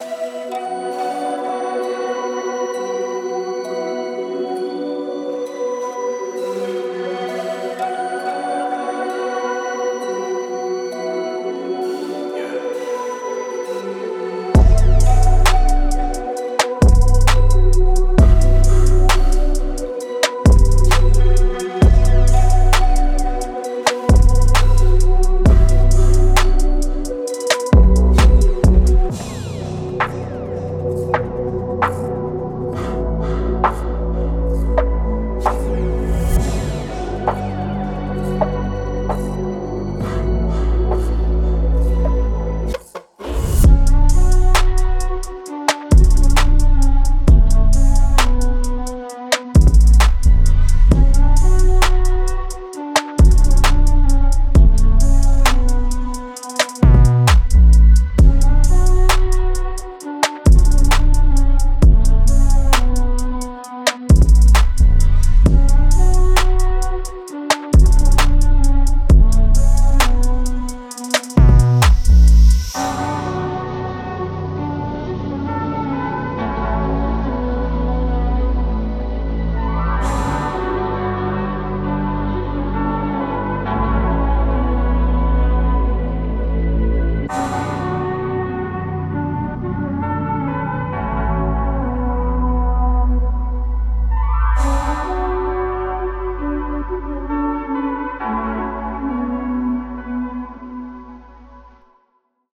(Dark Trap)
It’s the sound of mystery and allure.